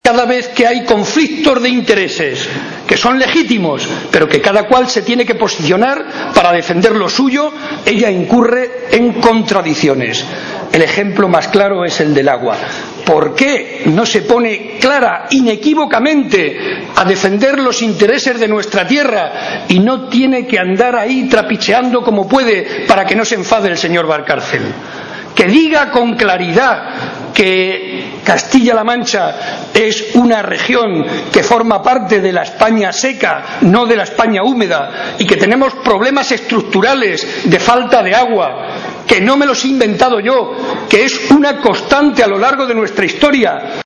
Momento del acto celebrado en Campo de Criptana.